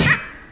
Playerstunned Sound Effect
playerstunned.mp3